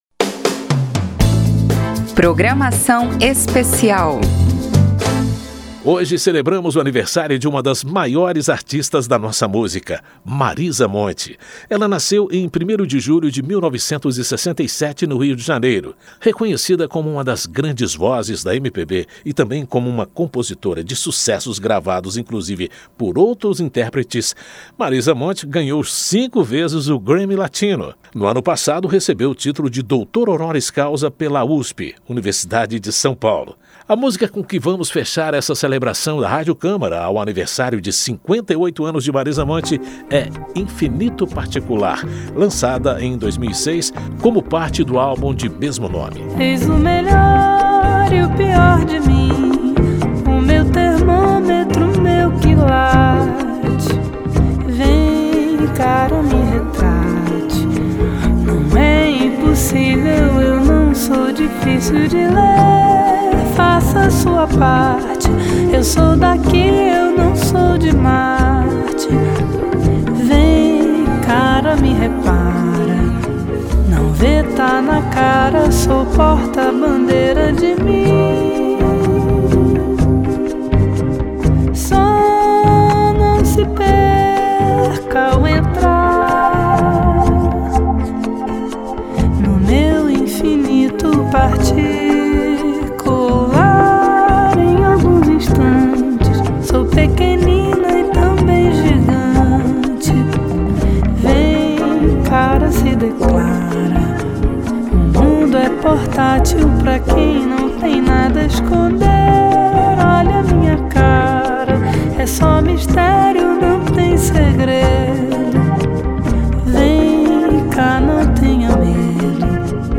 E, para comemorar o aniversário da carioca, nascida no dia  primeiro de julho de 1967, a Rádio Câmara preparou uma programação especial com seis blocos com informações e músicas.